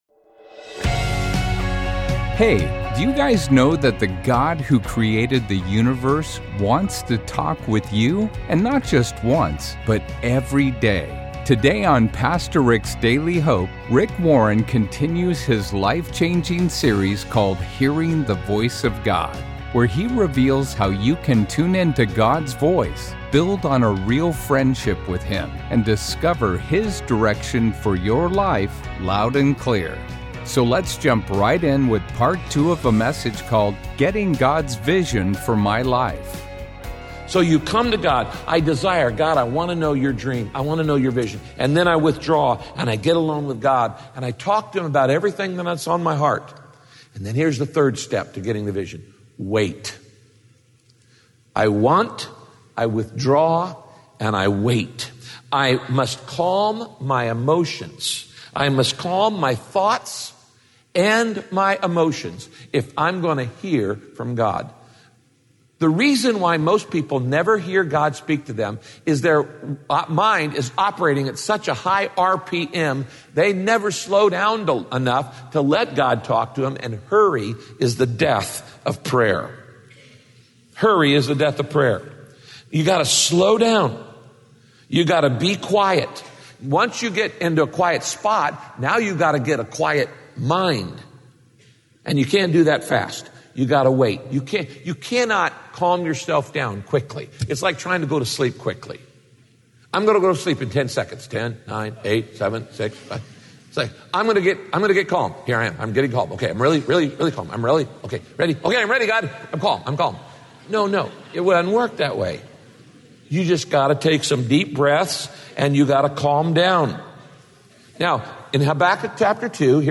It’s hard to hear from God when there’s too much noise in your life. In this message, Pastor Rick talks about the importance of getting alone with God so you can hear him speak.